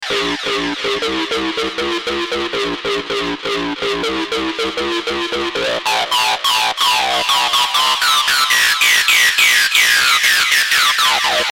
Download Rave sound effect for free.
Rave